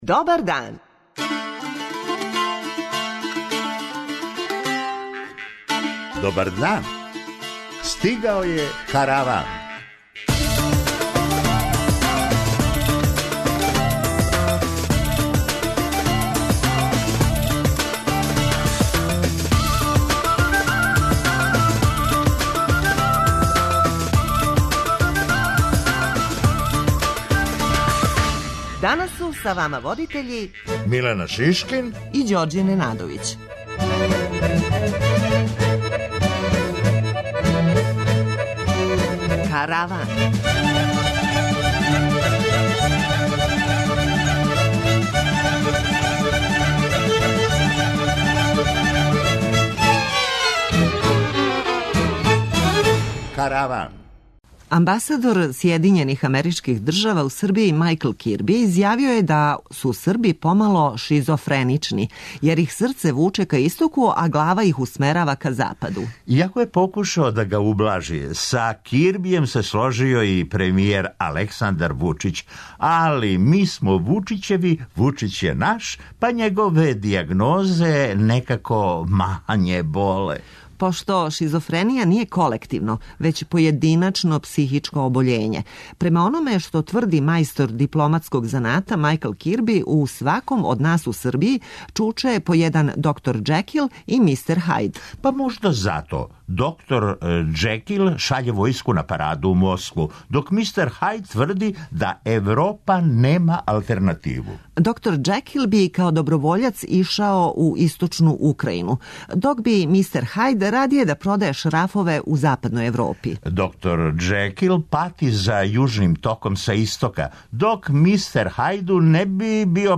преузми : 22.91 MB Караван Autor: Забавна редакција Радио Бeограда 1 Караван се креће ка својој дестинацији већ више од 50 година, увек добро натоварен актуелним хумором и изворним народним песмама. [ детаљније ] Све епизоде серијала Аудио подкаст Радио Београд 1 Роми између вере, идентитета и промена Подстицаји у сточарству - шта доносе нове мере Ђорђе покорио Тирану Хумористичка емисија Хумористичка емисија